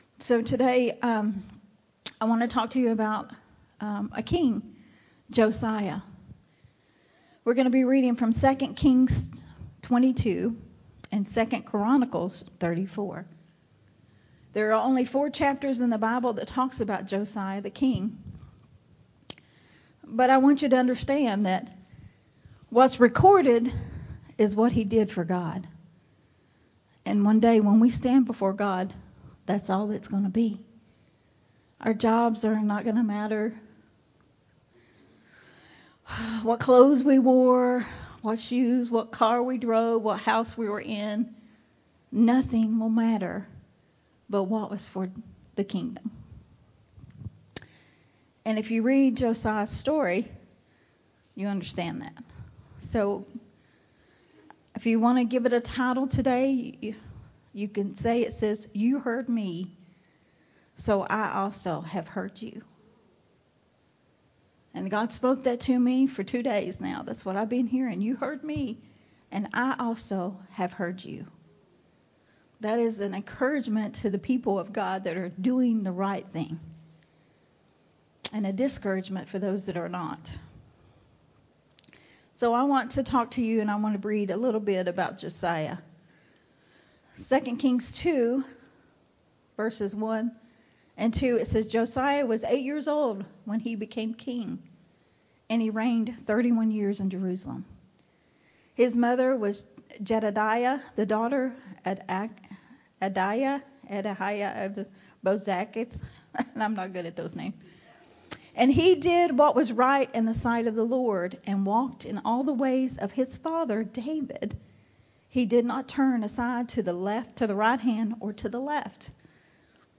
A Sunday Morning Refreshing teaching
recorded at Unity Worship Center on May 7th